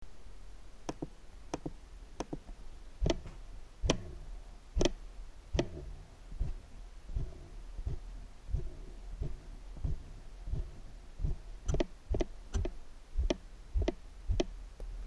Super tylius pagrindinius mygtukus galima priskirti kaip didelį privalumą, tačiau tylios pelės koncepciją sudagina labai triukšmingi forward ir back mygtukai. Nesukant ratuko agresyviai išlaikoma visiška tyla, tačiau pasukus agresyviau pasigirsta barkštelėjimas – horizontalia kryptimi ratukas yra per laisvas.
Ratuko paspaudimas + scroll